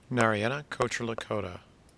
Dr. Narayana Kocherlakota (Nair-ah-yah-nah Koach-er-lah-ko-tah